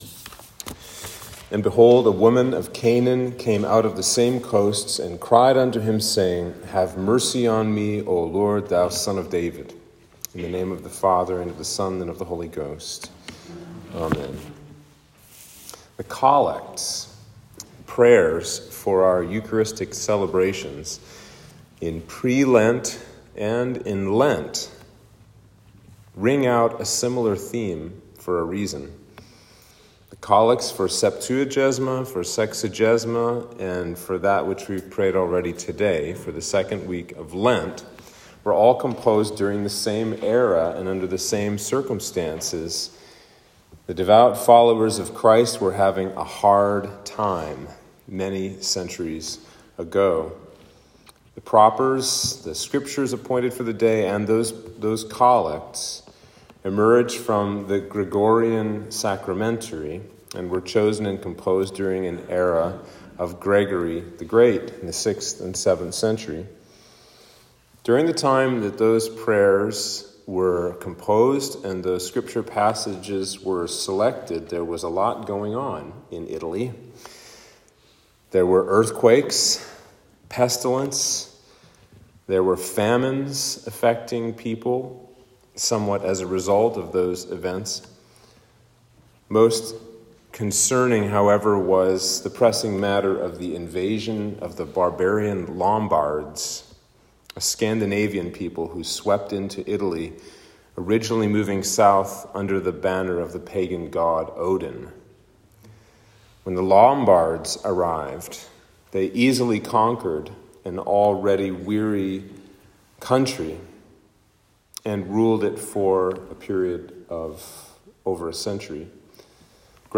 Sermon for Lent 2